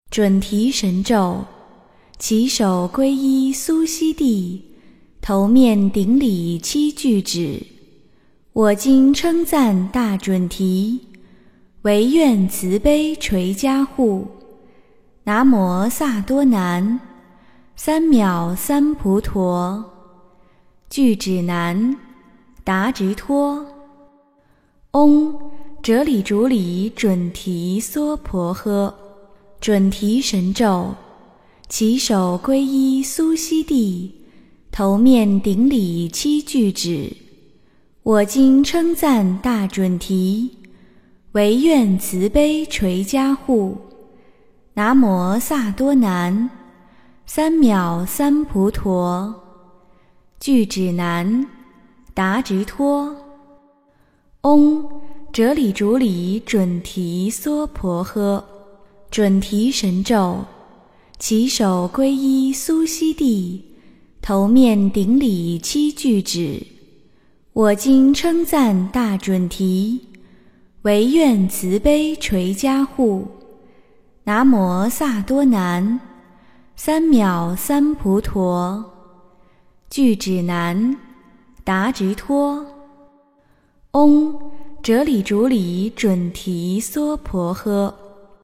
诵经
佛音 诵经 佛教音乐 返回列表 上一篇： 善天女咒 下一篇： 千手观音 相关文章 貧僧有話8說：人间因缘的重要--释星云 貧僧有話8說：人间因缘的重要--释星云...